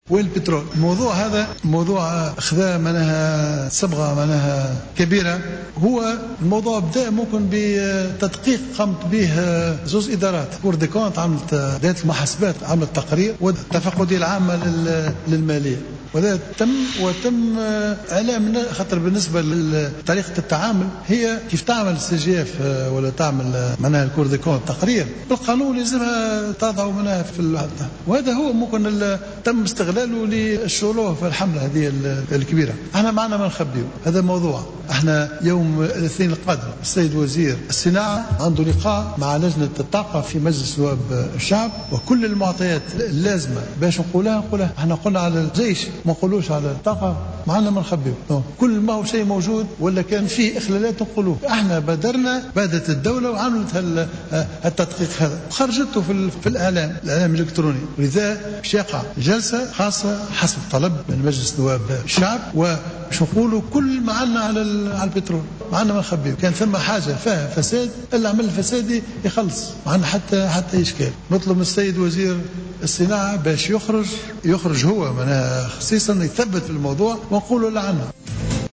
قال رئيس الحكومة الحبيب الصيد أمس الجمعة خلال جلسة الاستماع للحكومة وفي إطار تعليقه على حملة "وينو البترول" إن الحكومة ليس لديها ما تخفيه في هذا الملف،مشيرا إلى أنه ستتم محاسبة كل من تورط في الفساد إذا ما ثبت ذلك.